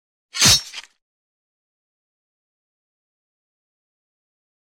SFX被剑砍伤出血的锋利声音效下载
SFX音效